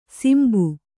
♪ simbu